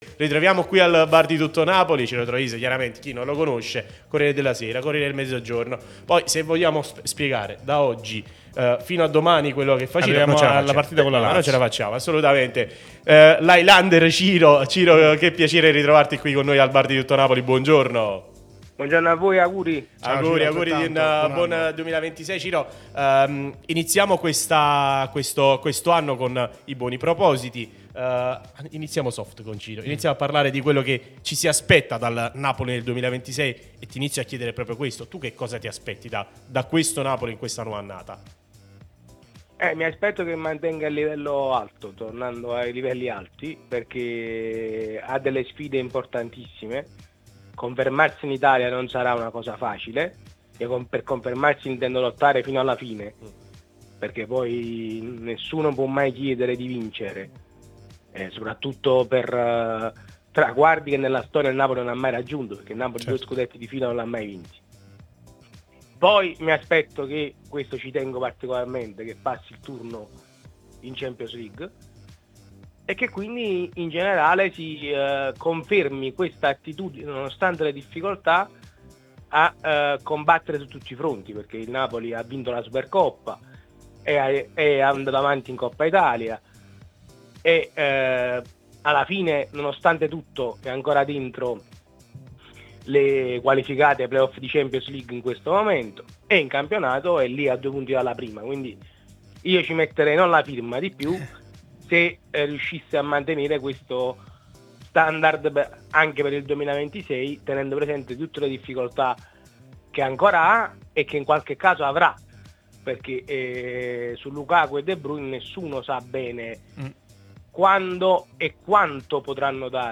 Radio TN CdM